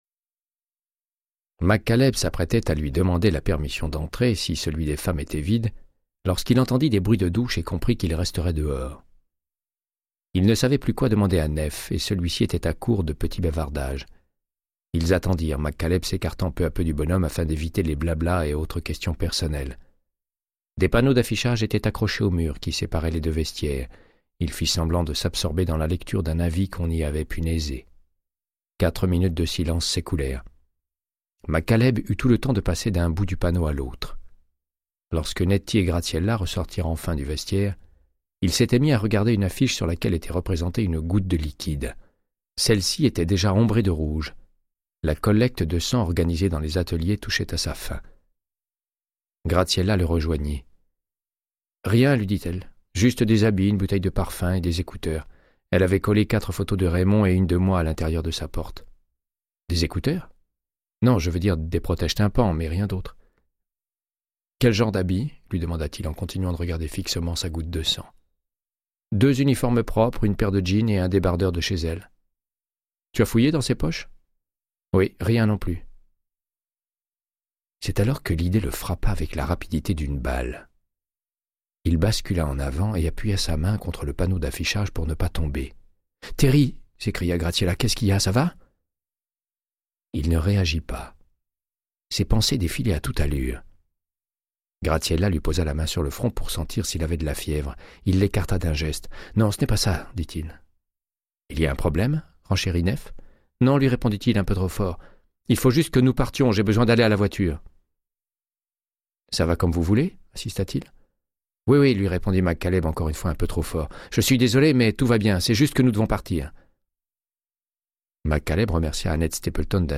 Audiobook = Créance de sang, de Michael Connelly - 111